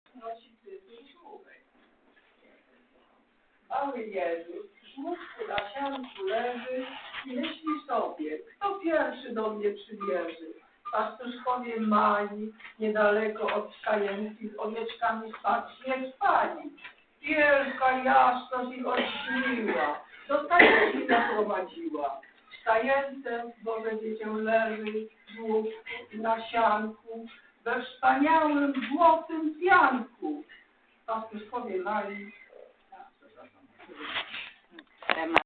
Oto wybrane fragmenty spotkania (przepraszamy za usterki w nagraniach) oraz teksty kolęd do ew. pobrania i foto-galeria.